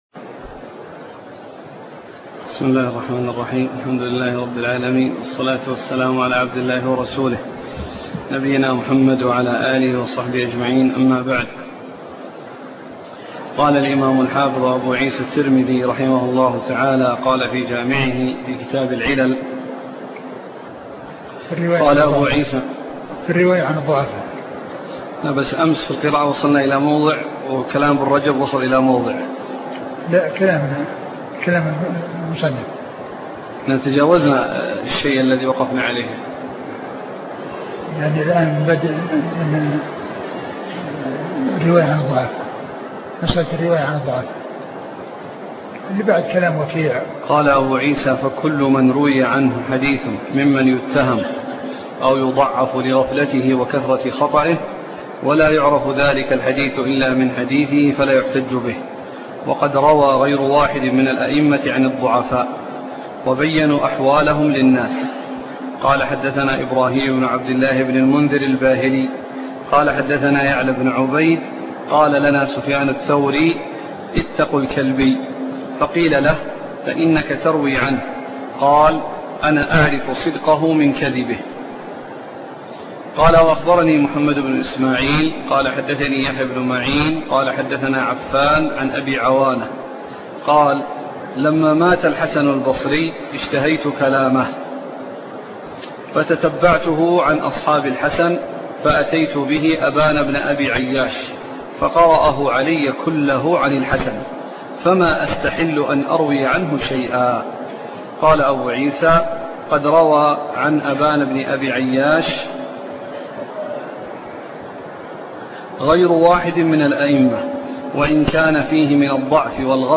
سنن الترمذي شرح الشيخ عبد المحسن بن حمد العباد الدرس 426